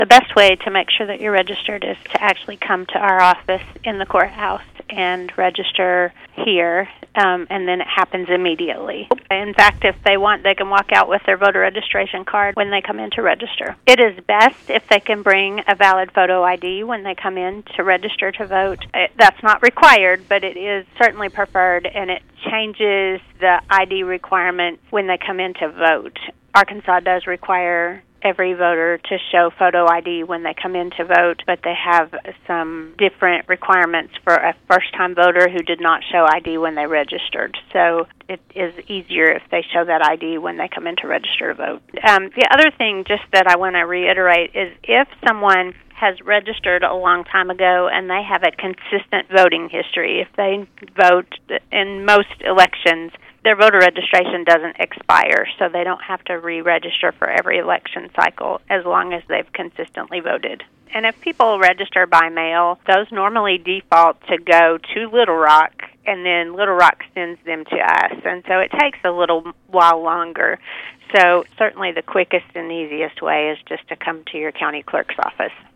Baxter County Circuit Court Clerk Canda Reese spoke with KTLO-FM Monday to talk about the best way to make sure you are registered.